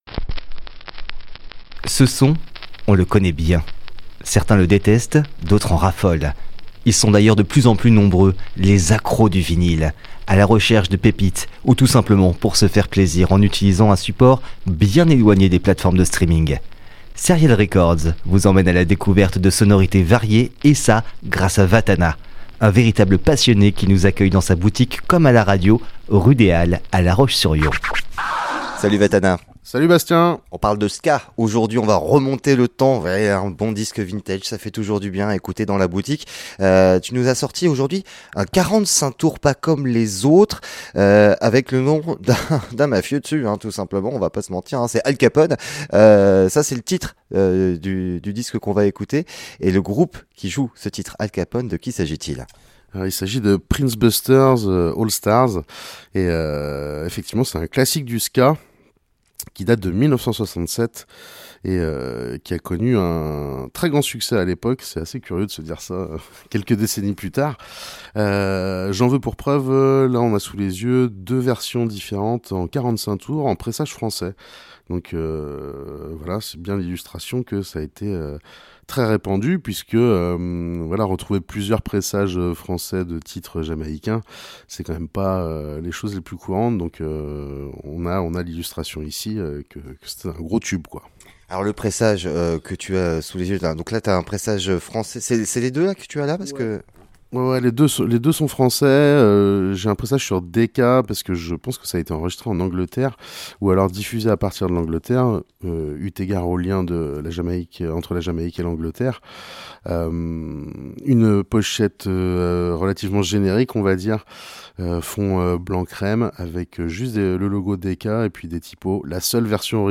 Plongez dans le son de la fin des années 60 avec des jamaïcains survoltés.